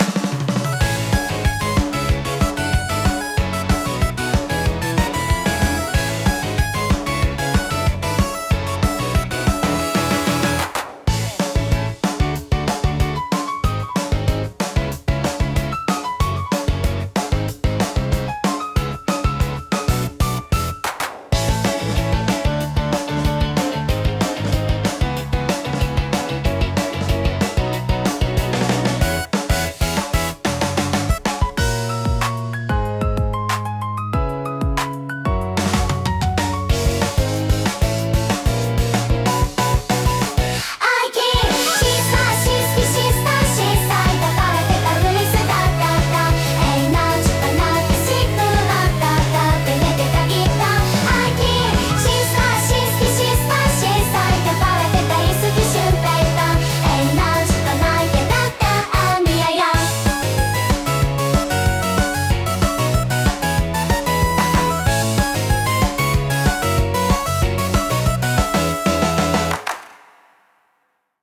親しみやすく軽快な空気感を演出したい場面で活躍します。